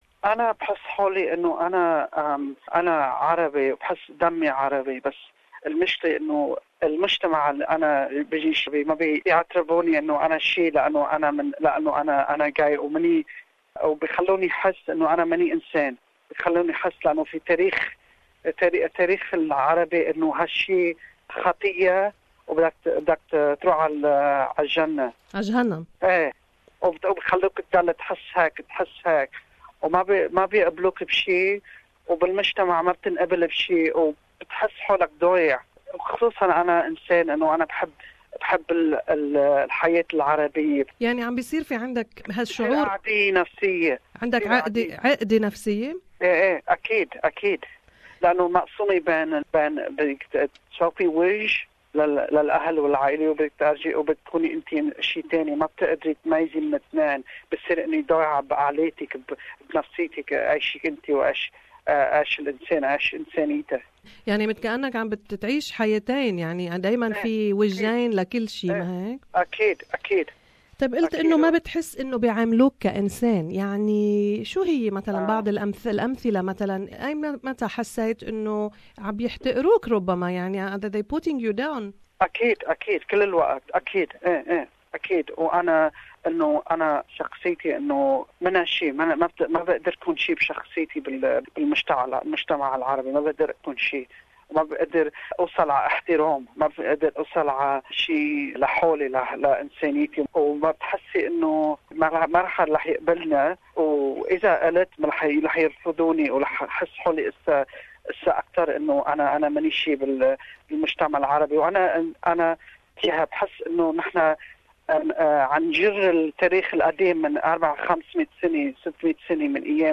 Here is the transcription of the interview: